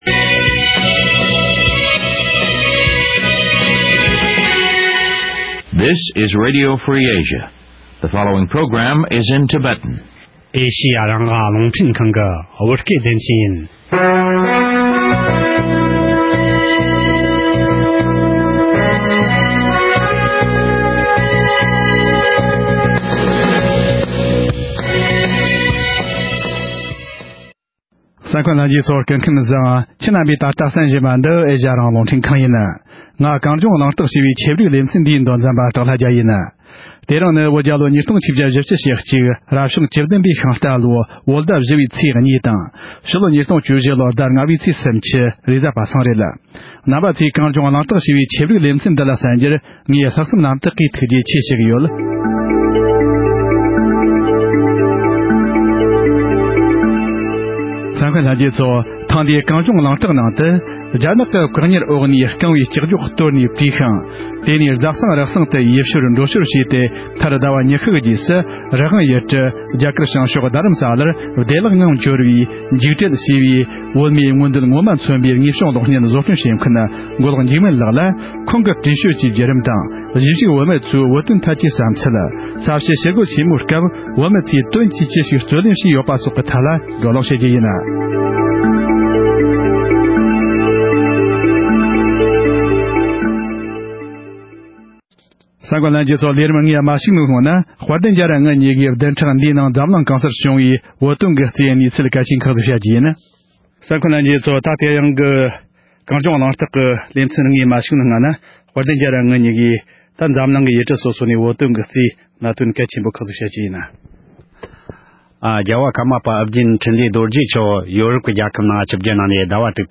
གཞིས་བཞུགས་བོད་མི་ཚོའི་བོད་དོན་ཐད་ཀྱི་དགོངས་ཚུལ་སོགས་ཀྱི་སྐོར་གླེང་མོལ་ཞུས་པ།